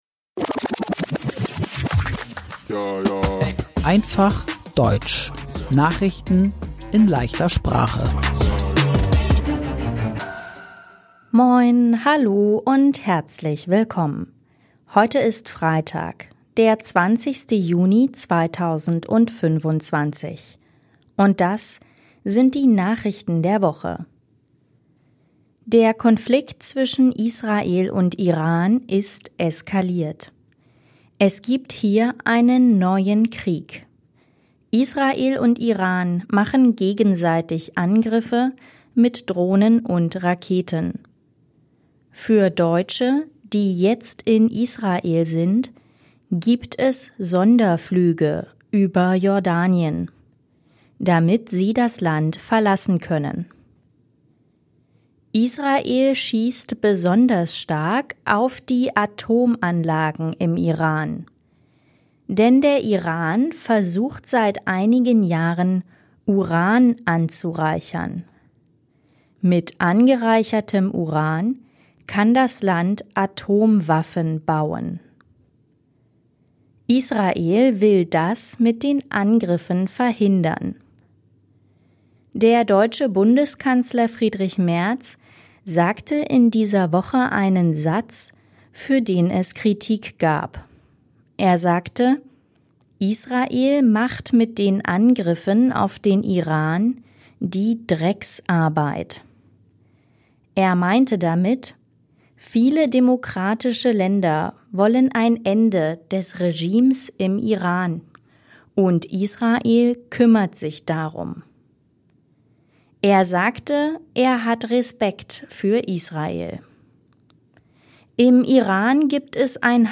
Krieg zwischen Israel und Iran ~ Einfaches Deutsch: Nachrichten in leichter Sprache Podcast